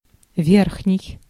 Ääntäminen
IPA: /ˈvʲerxnʲɪj/